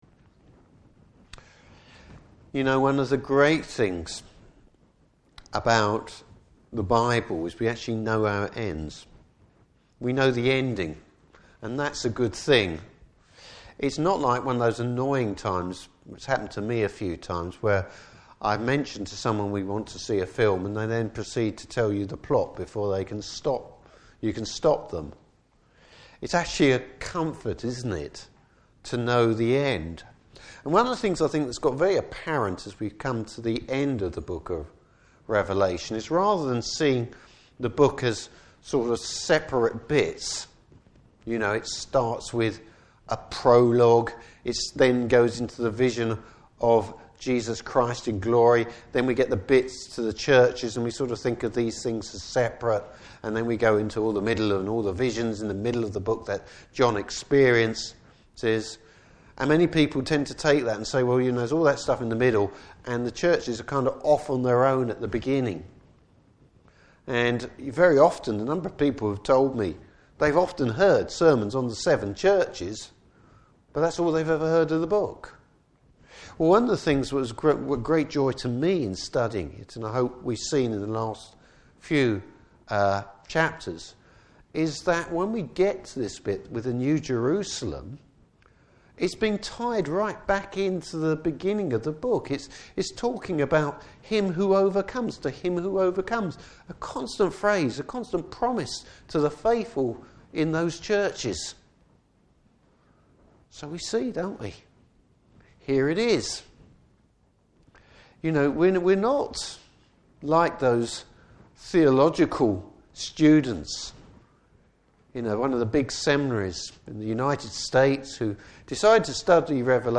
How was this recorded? Revelation 22:7-17 Service Type: Evening Service Bible Text